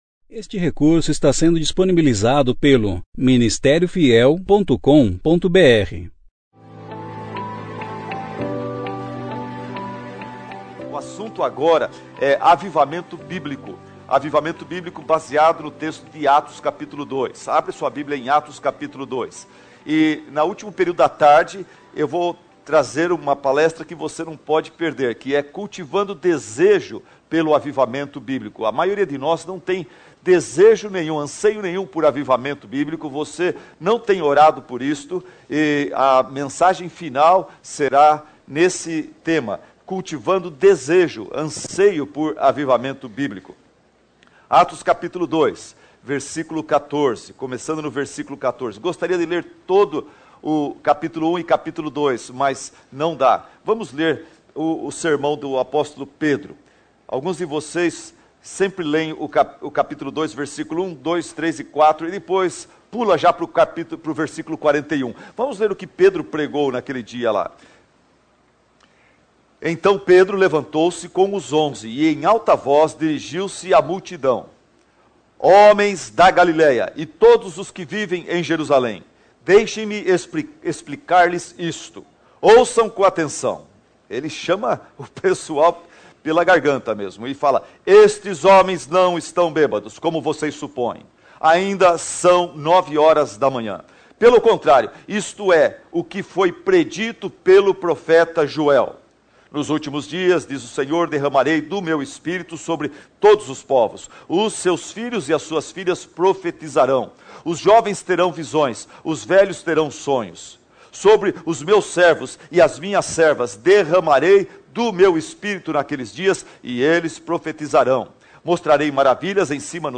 Conferência Fiel Juntos em Cristo – Recife - Ministério Fiel
Palestra 1 Jonathan Edwards e a Crítica ao Avivamento Assista